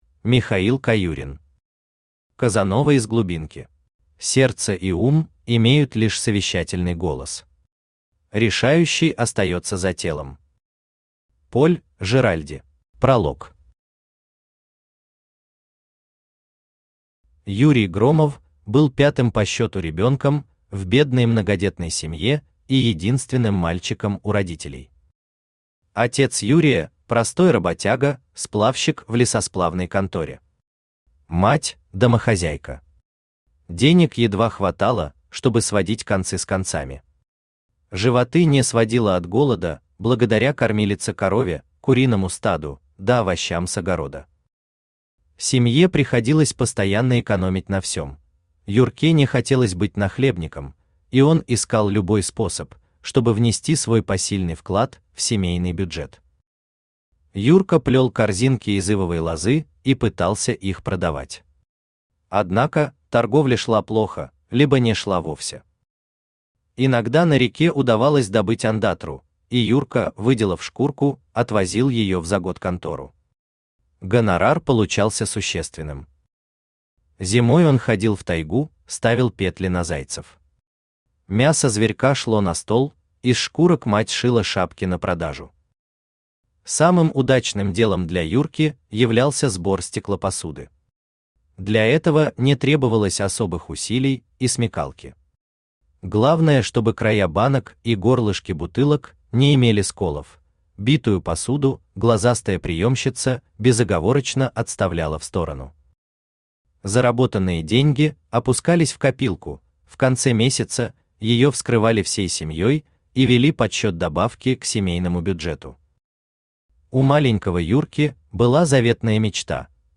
Аудиокнига Казанова из глубинки | Библиотека аудиокниг
Aудиокнига Казанова из глубинки Автор Михаил Александрович Каюрин Читает аудиокнигу Авточтец ЛитРес.